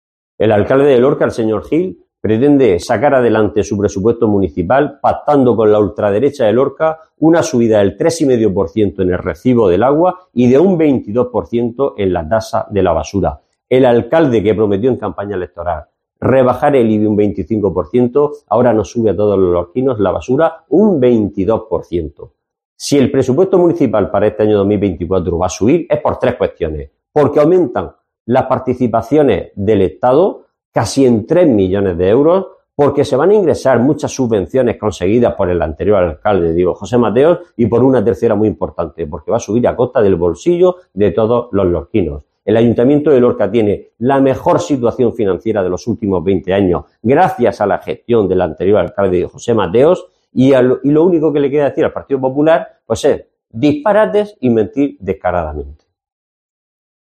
Isidro Abellán, concejal del PSOE en Lorca